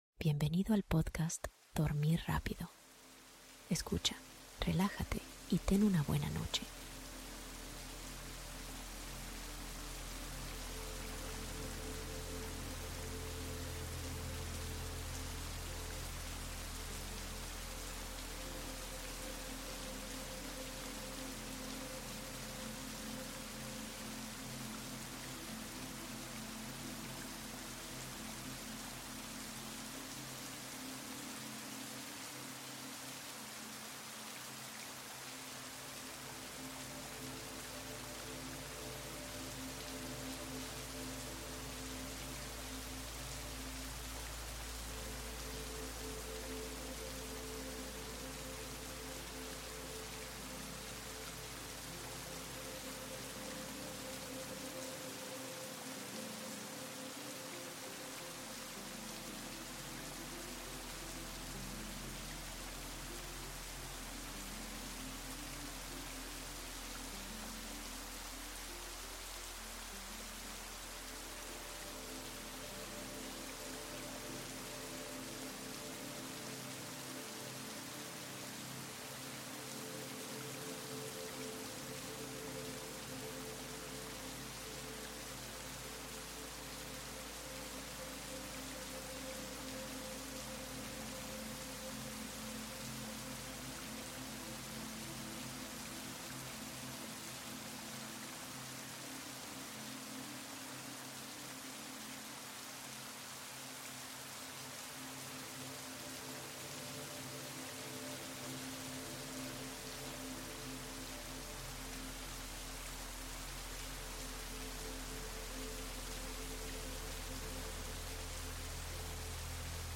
Mezcla Perfecta de LLUVIA y MÚSICA para un SUEÑO Profundo